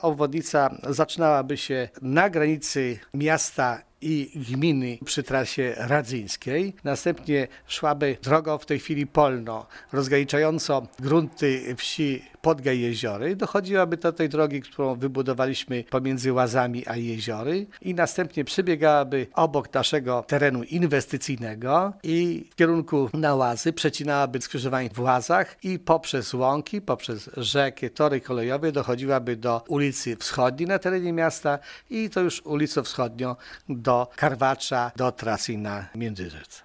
O tym którędy będzie przebiegać nowa droga informuje zastępca wójta Gminy Łuków Wiktor Osik: